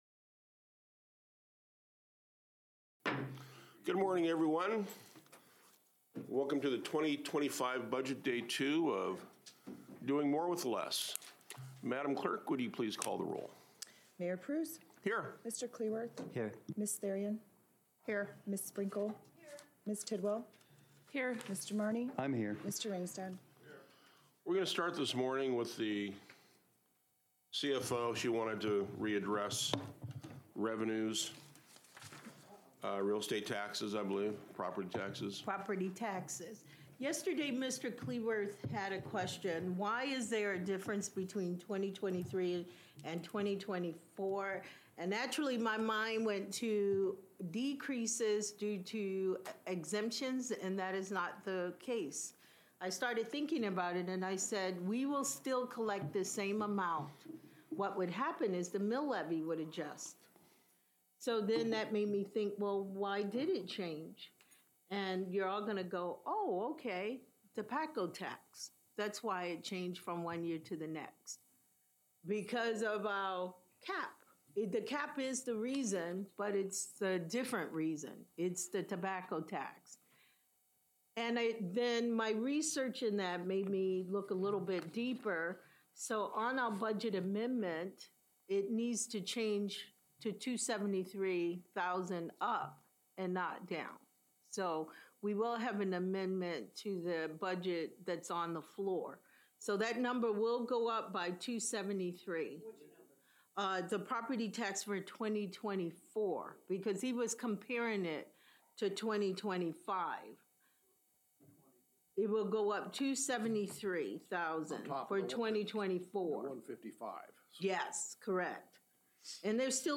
Council Budget Meeting